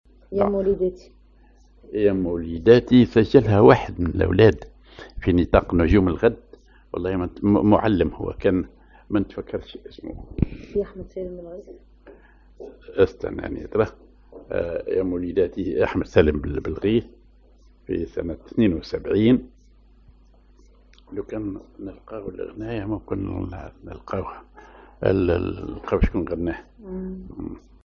Maqam ar بياتي نوا
genre أغنية